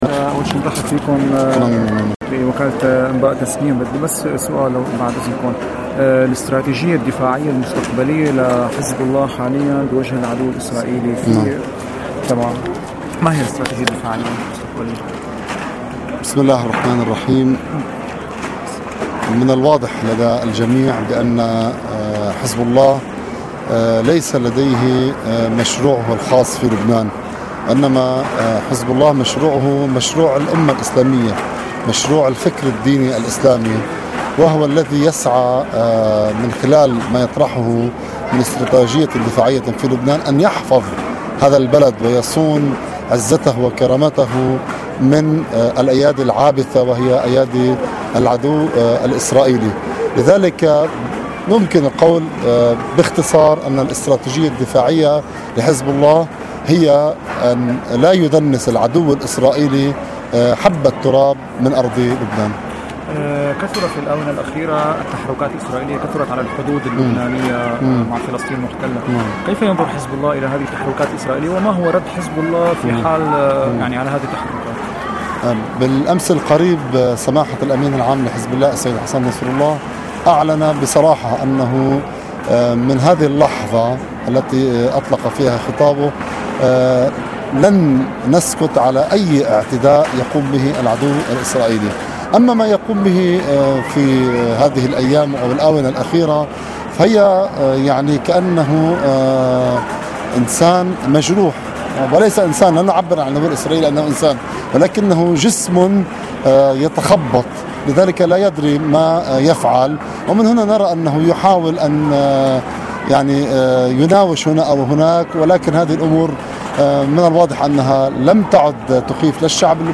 در مصاحبه با خبرنگار اعزامی تسنیم به دمشق در حاشیه مراسم ارتحال حضرت امام(ره) در حرم مطهر حضرت زینب(س)